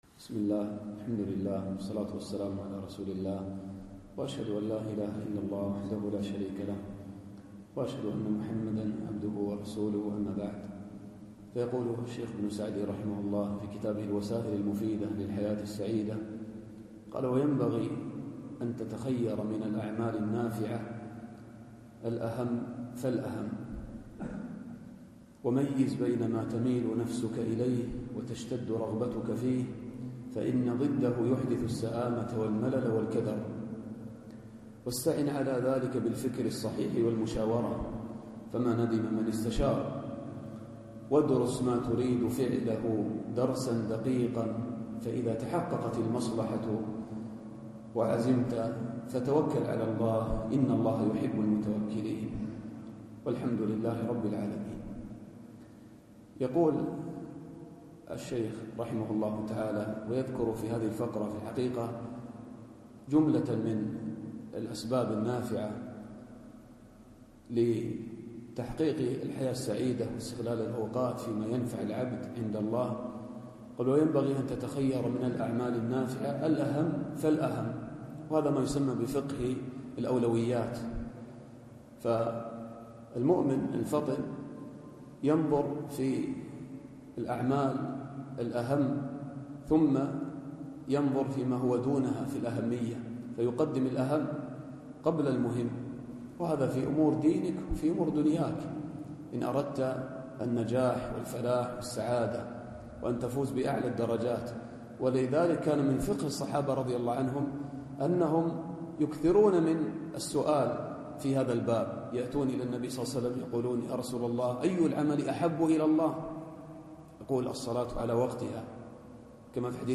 الدرس الرابع والعشرون